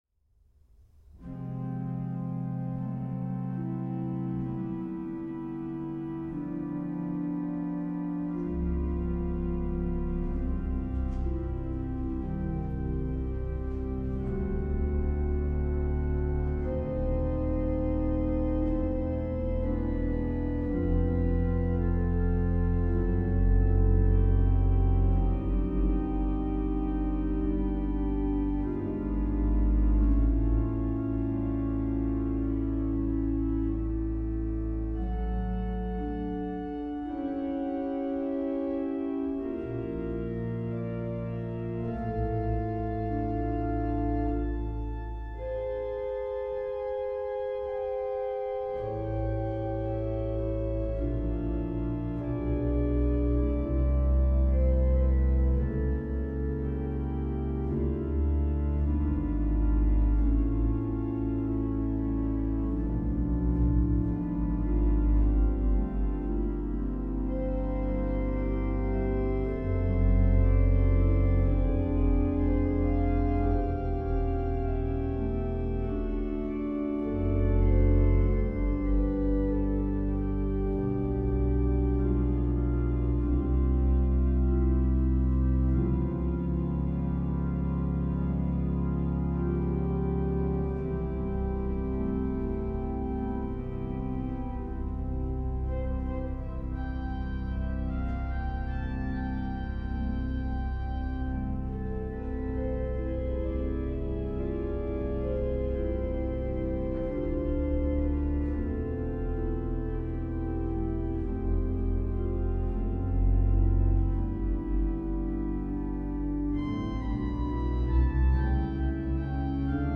Choralfantasie-Wer-unterm-Schutz.mp3